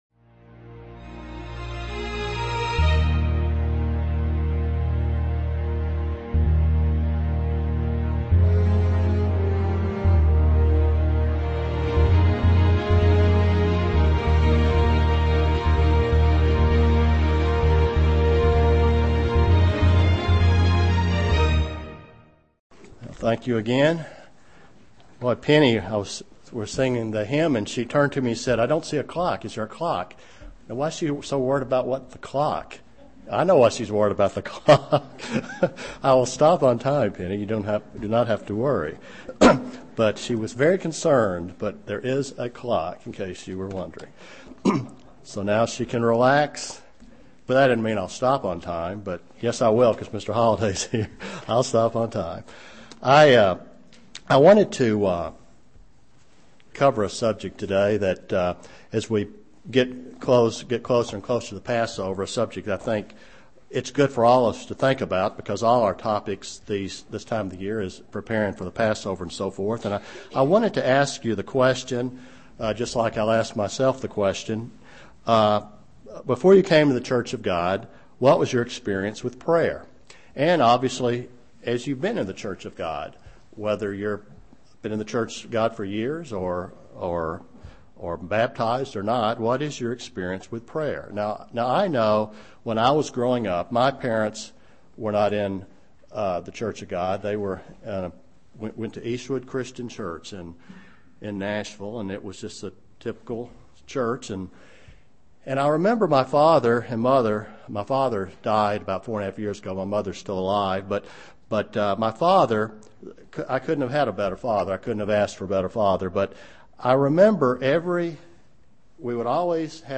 Given in Chattanooga, TN
Luke 18:1-8 UCG Sermon Studying the bible?